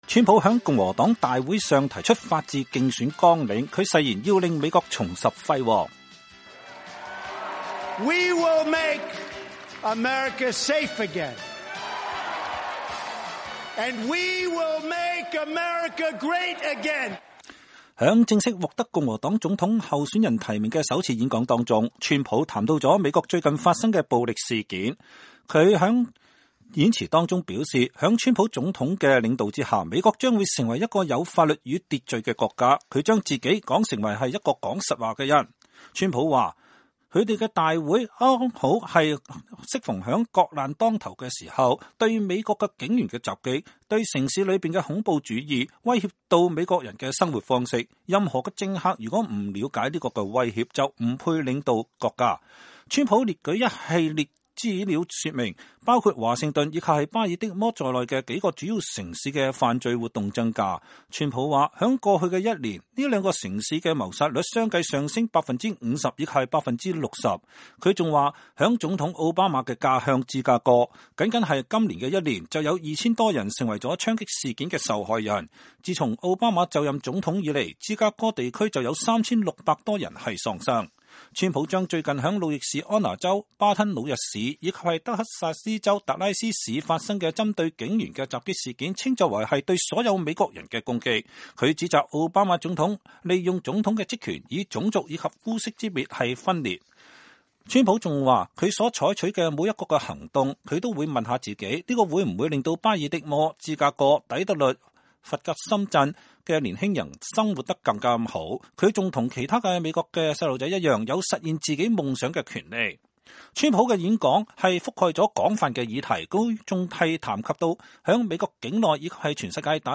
川普星期四晚在共和黨大會上談內政外交